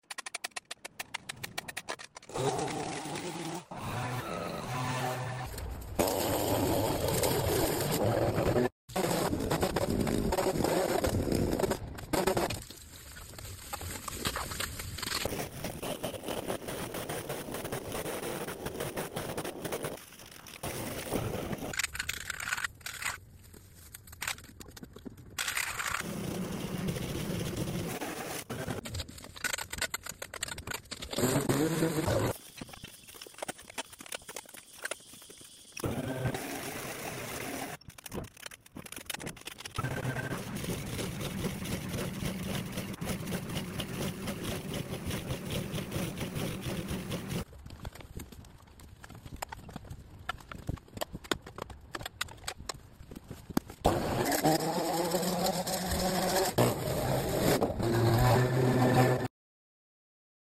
Satisfying lawn Cleaning | asmr